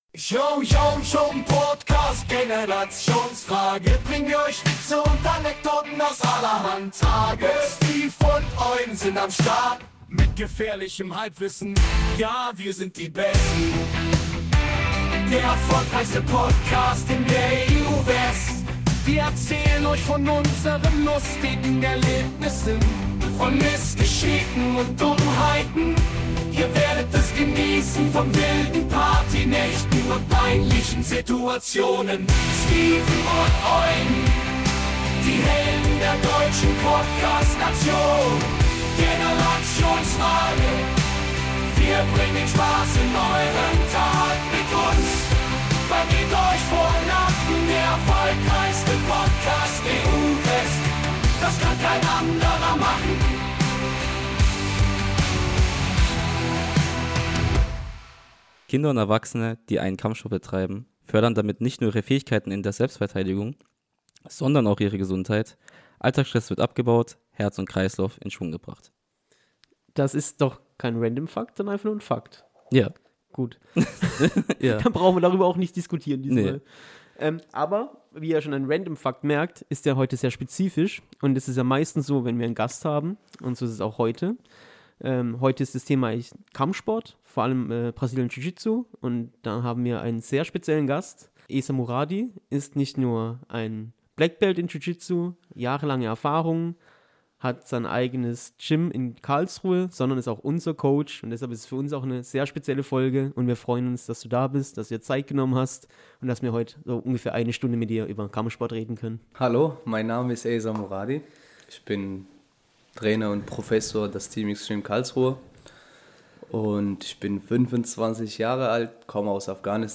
Wie haben diese Folge nicht in unserer gewohnten Umgebung aufgenommen, verzeiht also bitte falls der Ton nicht ganz so gut ist wie sonst. Desweiteren war unser Gast etwas erkältet als auch hier bitte entschuldigung falls ab und an gehustet wird.